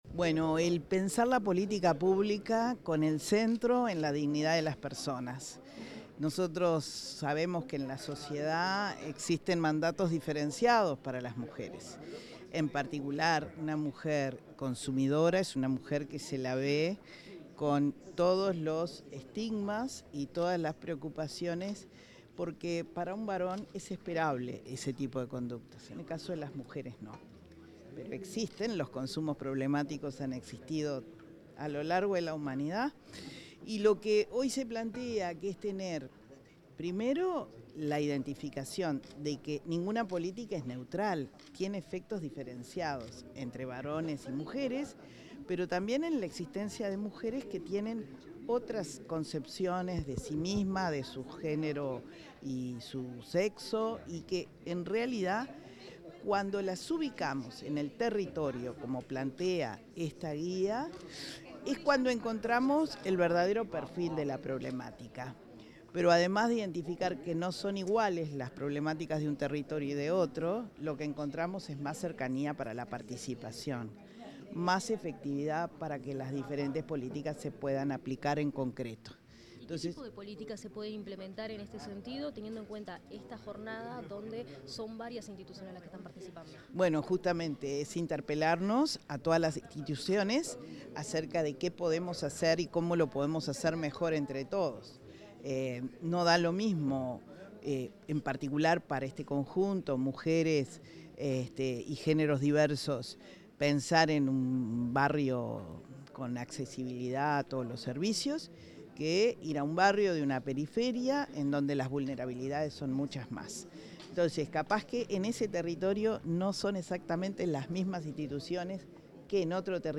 Declaraciones de la directora del Instituto Nacional de las Mujeres, Mónica Xavier
xavier.mp3